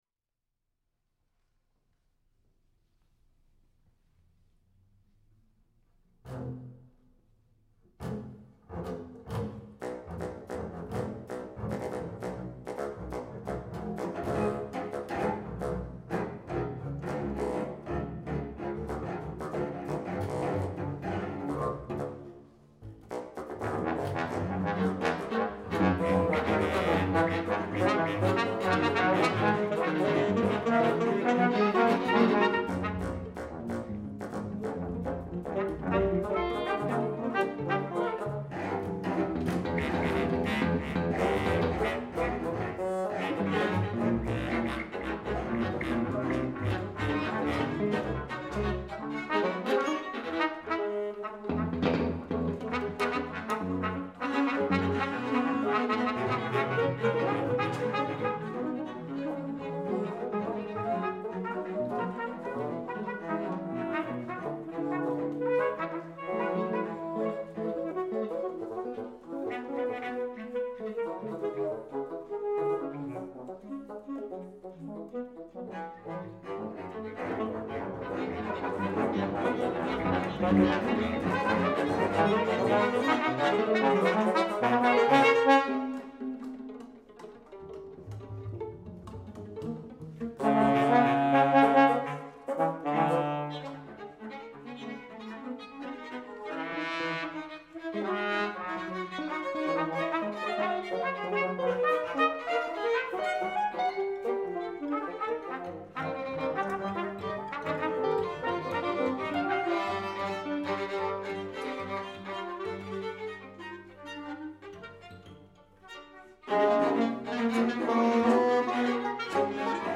fl/picc/bass fl, cl/bass cl, sopr sax/alto sax, bsn, tpt, tbn
vl 1, vl 2, vla, cello, bass, electric guitar
With “lightfastness,” I wrote music that changes color over time.
Premiered at Bowling Green State University on March 24, 2018.